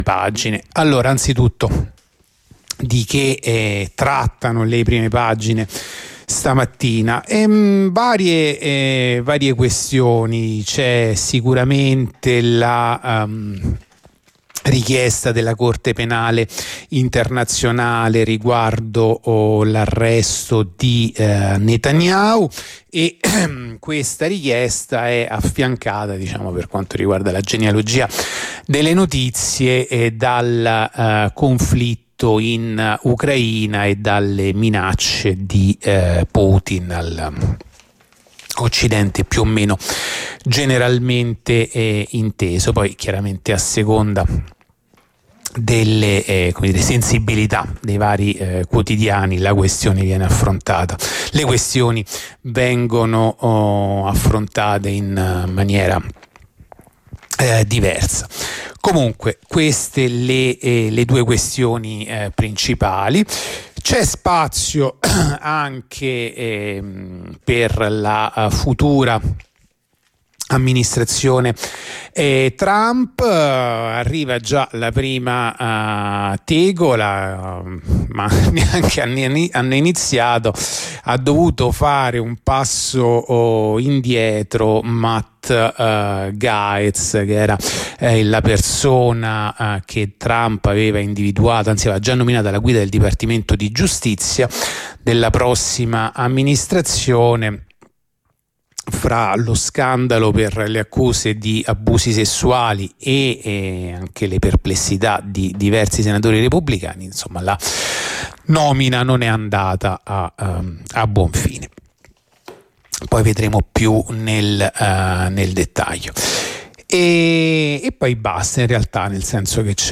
La rassegna stampa di radio onda rossa andata in onda venerdì 24 novembre 2024